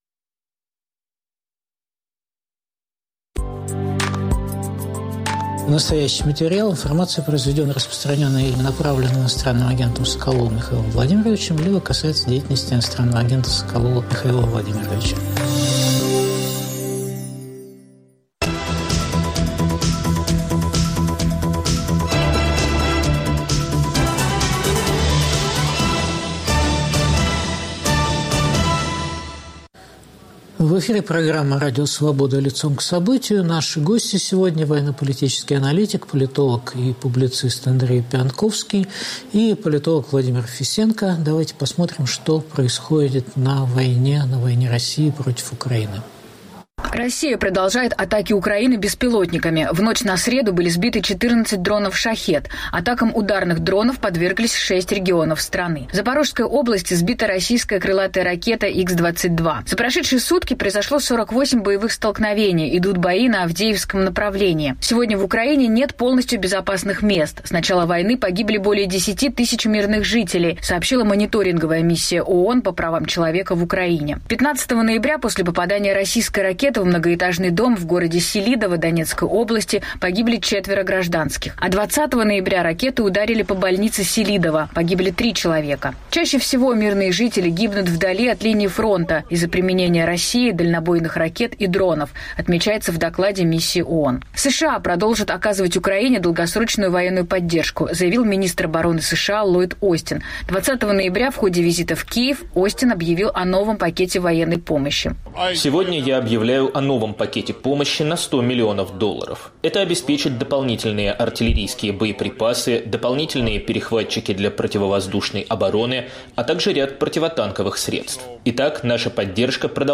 Запад в украинском противостоянии Путину. В эфире Андрей Пионтковский, Владимир Фесенко